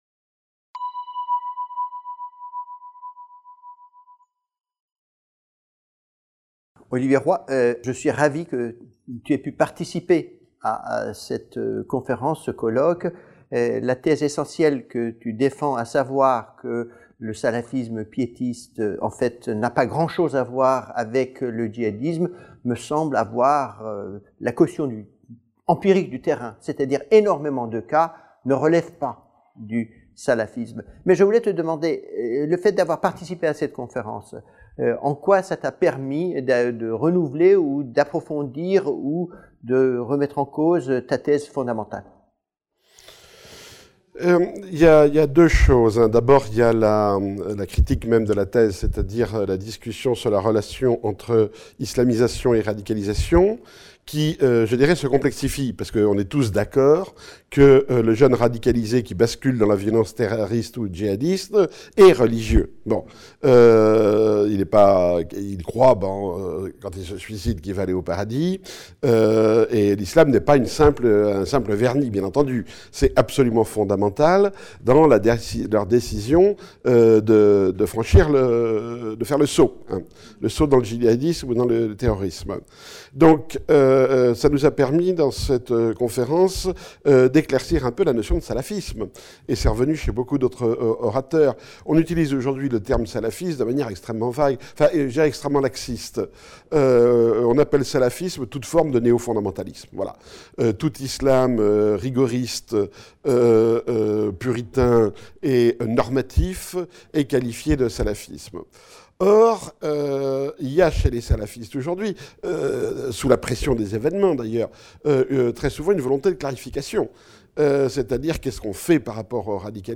Entretien
Olivier Roy (Intervention)